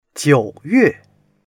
jiu3yue4.mp3